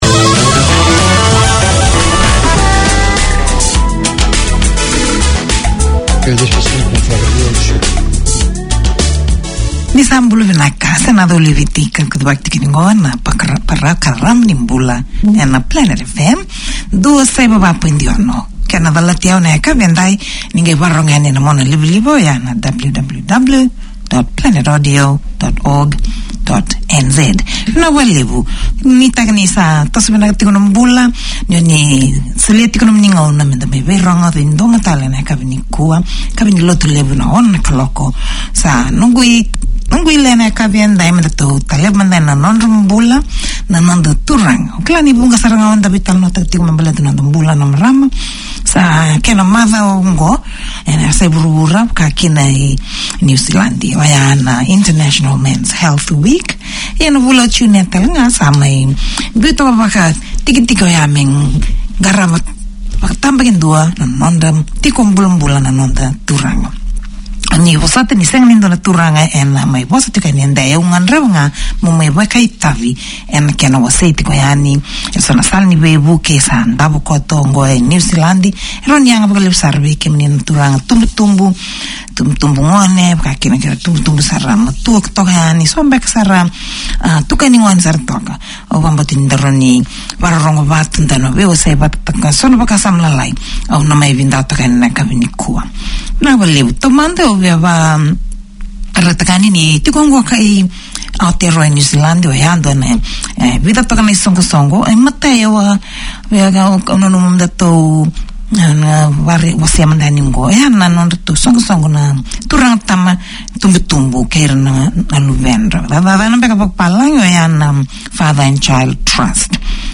Community Access Radio in your language - available for download five minutes after broadcast.
Pasifika Wire Live is a talanoa/chat show featuring people and topics of interest to Pasifika and the wider community.